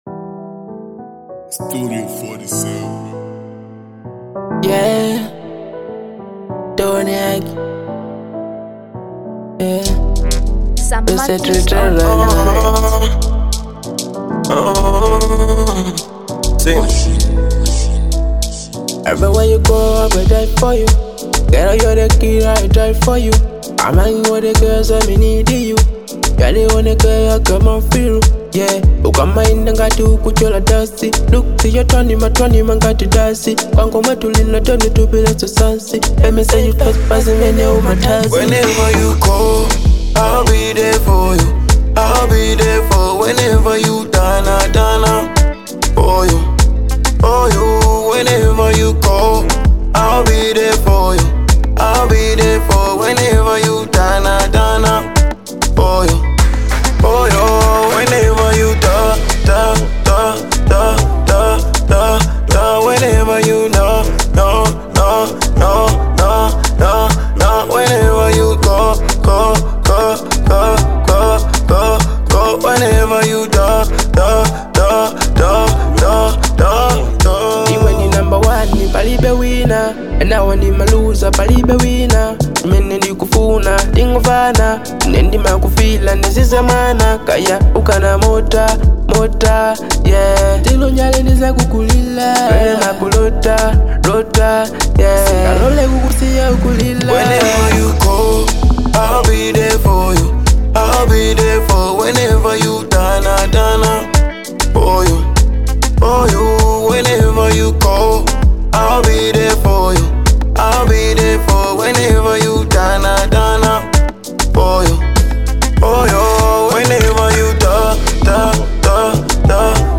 Afro-Swing